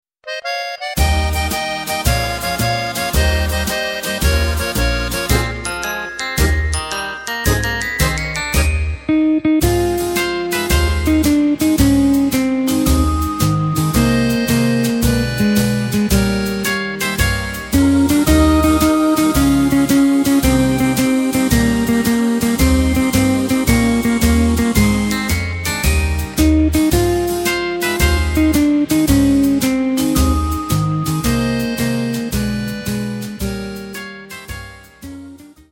Takt:          2/4
Tempo:         111.00
Tonart:            Ab
Schlager Oldie aus dem Jahr 1957!
Playback mp3 Demo